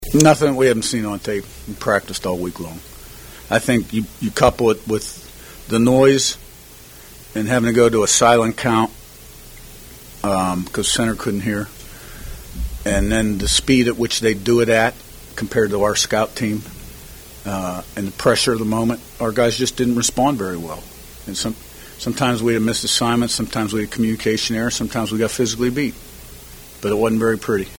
Indiana Hoosiers Postgame Press Conference with head coach Curt Cignetti after loss at #2 Ohio State, 38-15